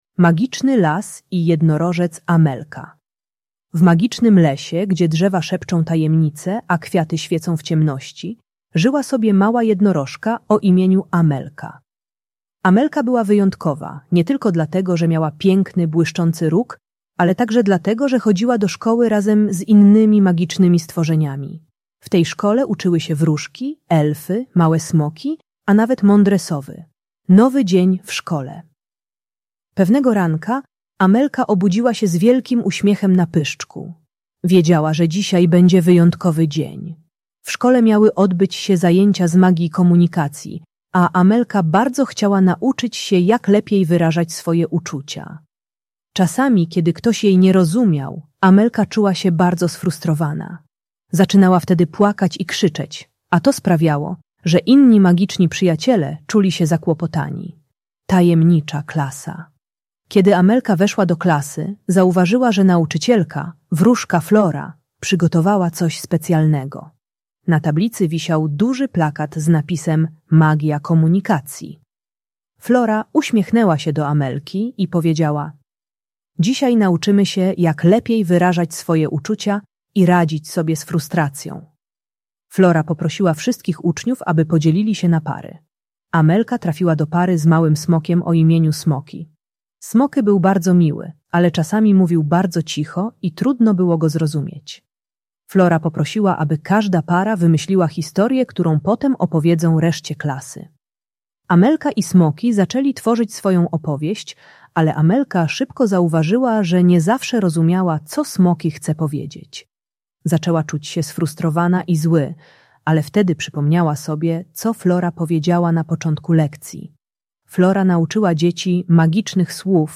Magiczny Las i Jednorożec Amelka - Bunt i wybuchy złości | Audiobajka